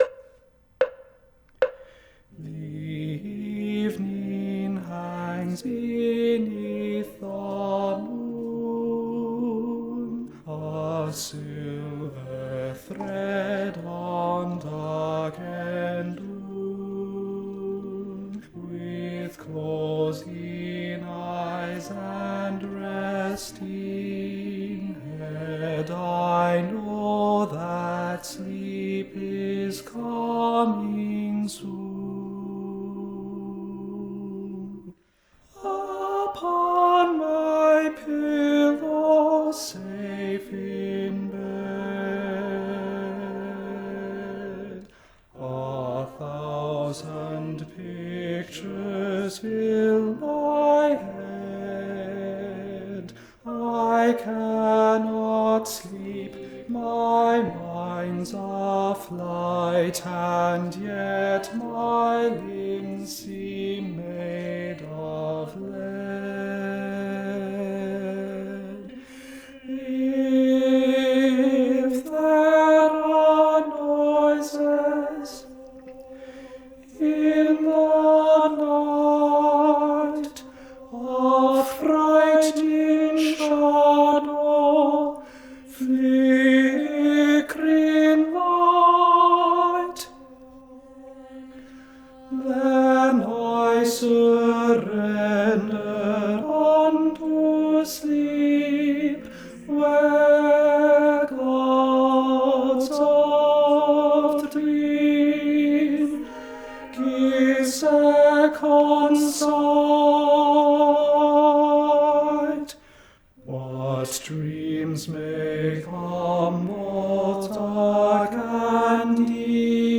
- Œuvre pour chœur à 8 voix mixtes (SSAATTBB)
Tenor 2 Live Vocal Practice Track